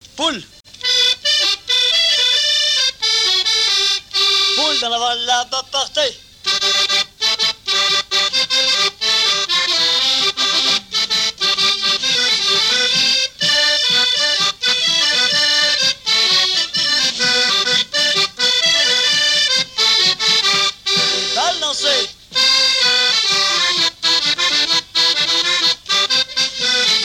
Mémoires et Patrimoines vivants - RaddO est une base de données d'archives iconographiques et sonores.
danse : quadrille : poule
Pièce musicale inédite